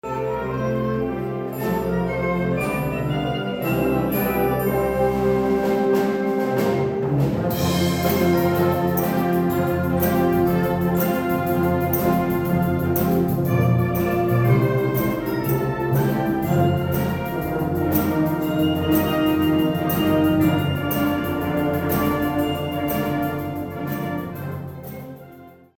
Emporia Municipal Band welcomes Emporians to annual Christmas concert Sunday
The band hosted its annual Christmas concert inside the Emporia Granada Theatre, performing various holiday medleys featuring music from around the world and some recognizable favorites.